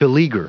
Prononciation du mot beleaguer en anglais (fichier audio)
Prononciation du mot : beleaguer